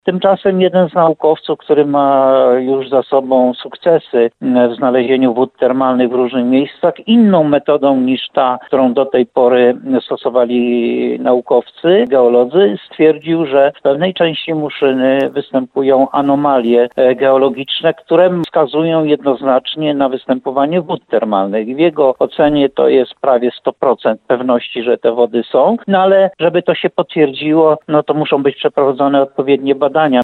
Jak dodaje burmistrz Muszyny, ostatnie badania zmieniają tę perspektywę.